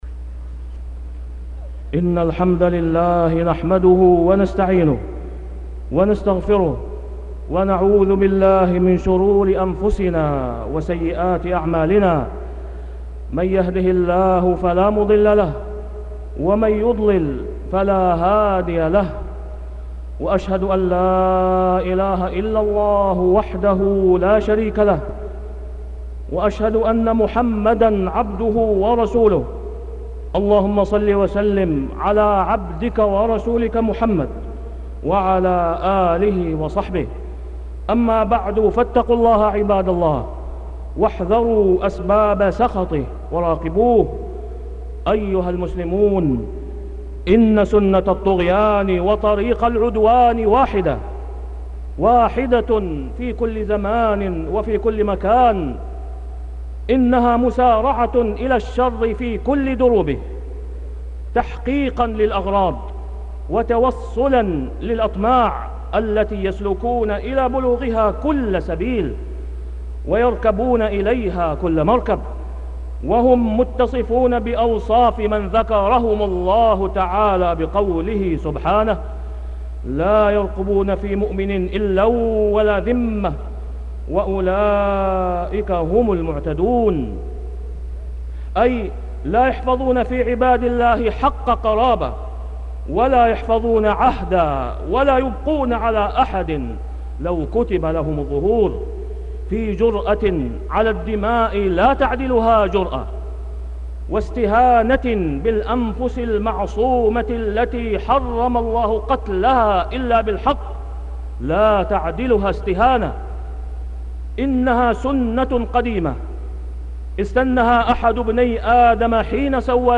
تاريخ النشر ٣ صفر ١٤٢٧ هـ المكان: المسجد الحرام الشيخ: فضيلة الشيخ د. أسامة بن عبدالله خياط فضيلة الشيخ د. أسامة بن عبدالله خياط تحريم الأنفس المعصومة